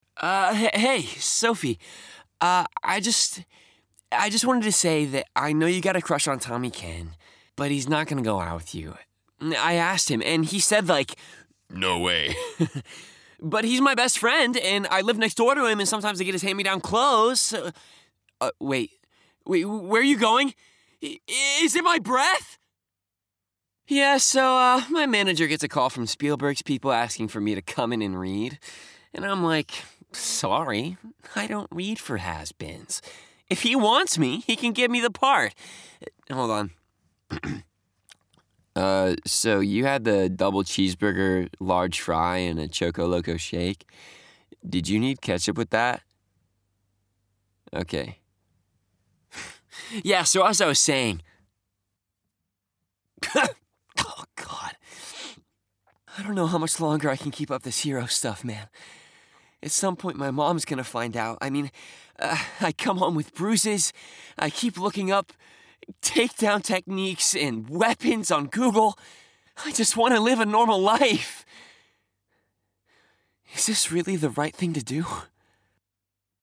VO / Animation
Teen Sample